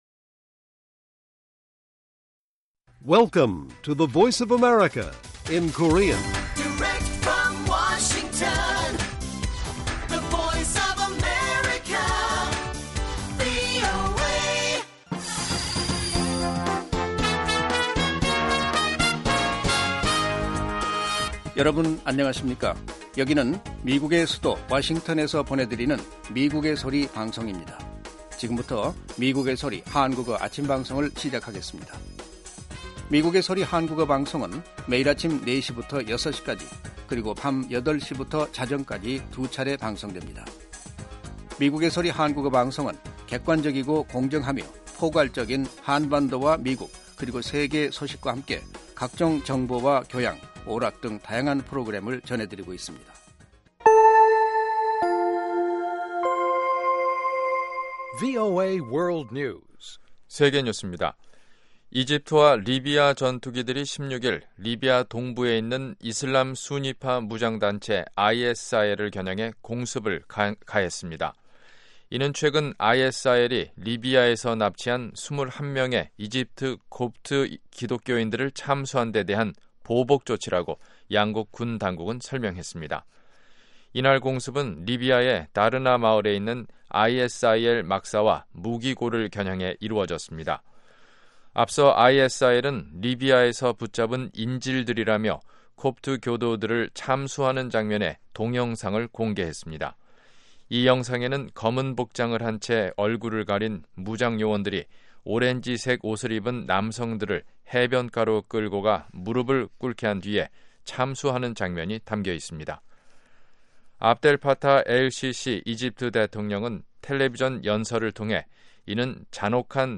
VOA 한국어 방송의 아침 뉴스 프로그램 입니다. 한반도 시간 매일 오전 4시부터 5시까지 방송됩니다.